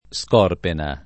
scorpena [Skorp$na; alla greca